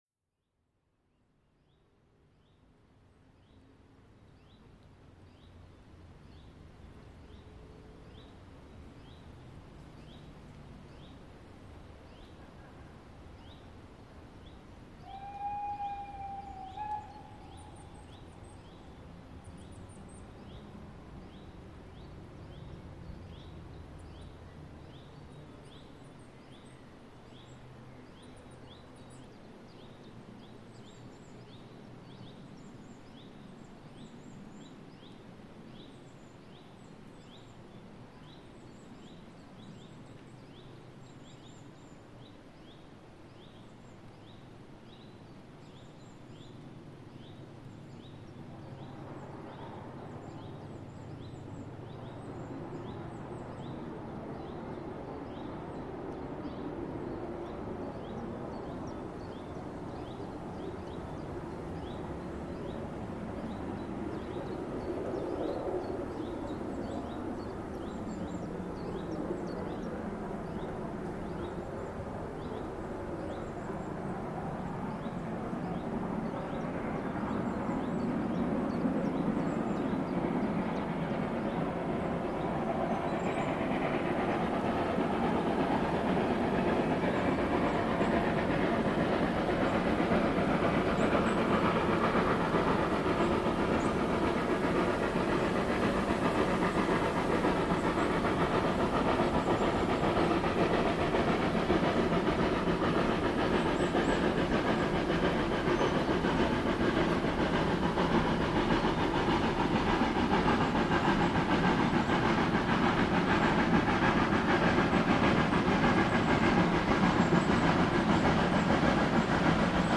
95 027  wieder mit zwar nur Standard-2-Wagen-Zug Richtung Rübeland, aber keinesfalls weniger spektakulär als in den jahren zuvor aufgenommen in dem tiefen Einschnitt im Wald am Eichenberg direkt an der steilsten Stelle der Strecke hinter Michaelstein, um 13:44h am 07.07.2024.